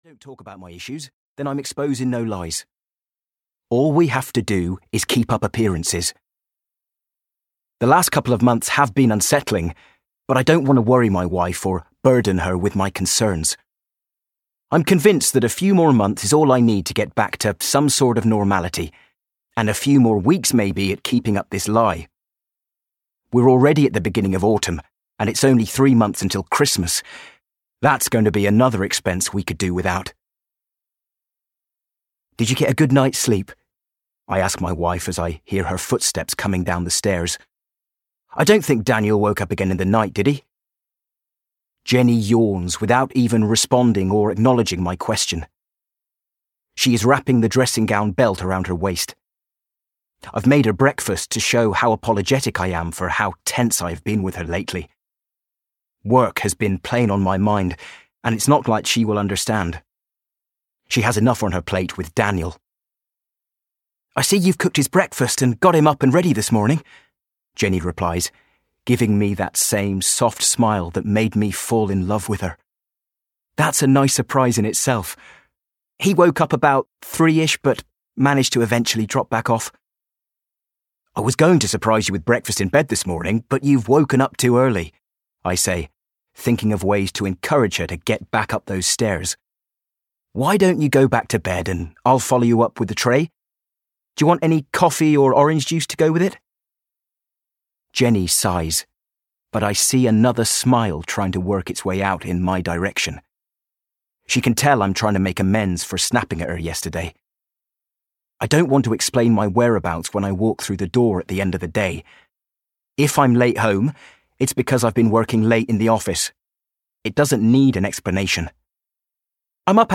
You Let Him In (EN) audiokniha
Ukázka z knihy